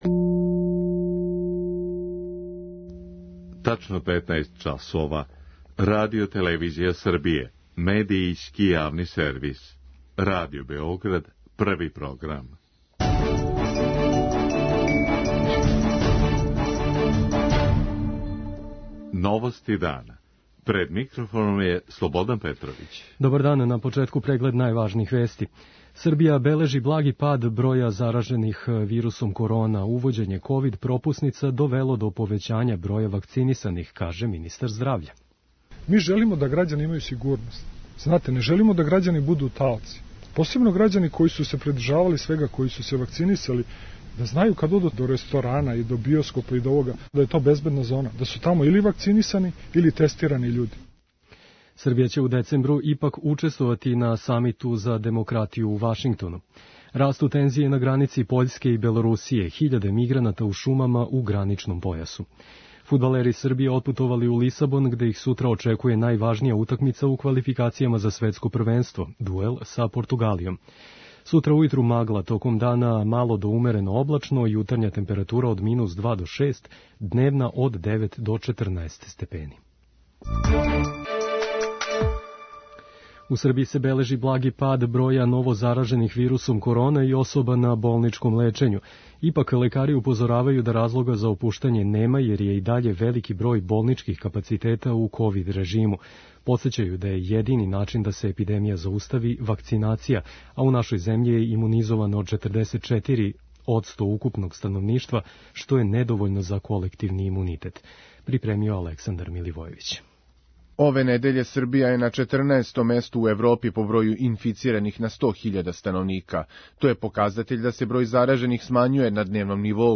Он је рекао је да је могуће продужење рока у коме ће ковид-пропуснице бити обавезне, јер се тако обезбеђује безбедна атмосфера за живот грађана. преузми : 6.32 MB Новости дана Autor: Радио Београд 1 “Новости дана”, централна информативна емисија Првог програма Радио Београда емитује се од јесени 1958. године.